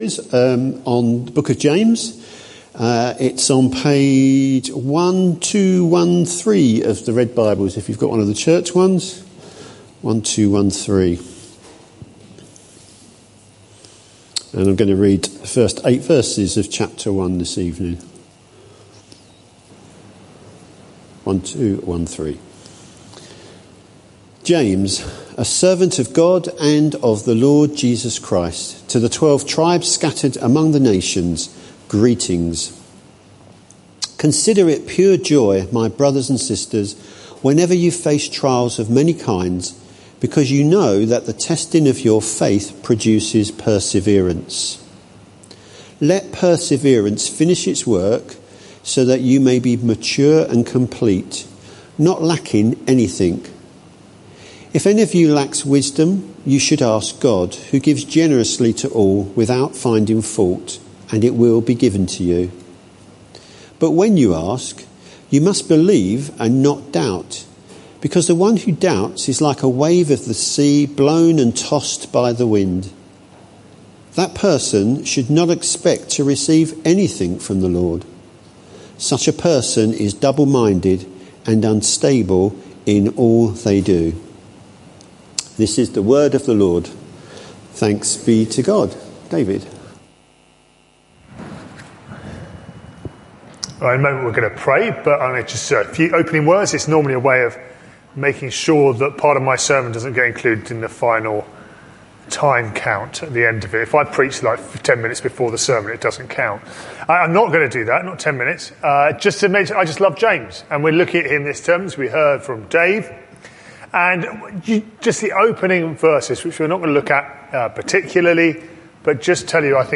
This sermon is part of a series: